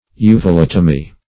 uvulatomy - definition of uvulatomy - synonyms, pronunciation, spelling from Free Dictionary Search Result for " uvulatomy" : The Collaborative International Dictionary of English v.0.48: Uvulatomy \U`vu*lat"o*my\, n. (Surg.) The operation of removing the uvula.